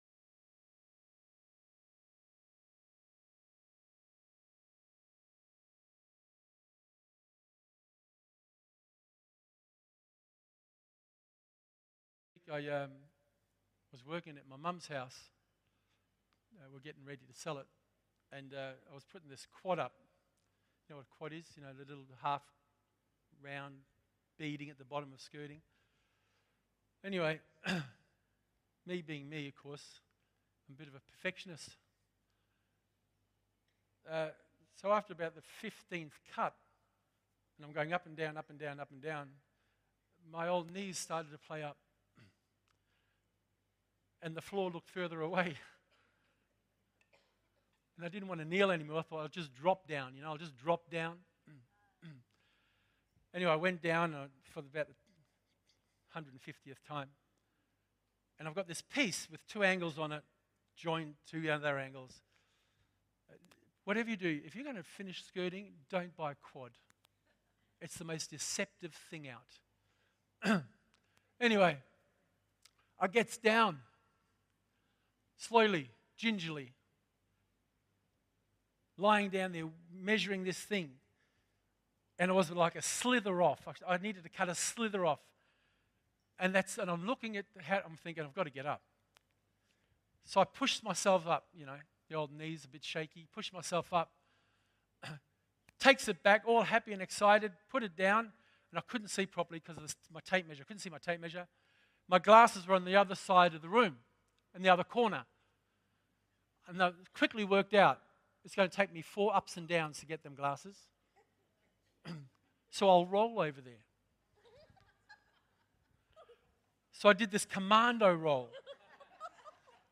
All sermons can now be viewed live or later via the Westpoint City Church YouTube Channel !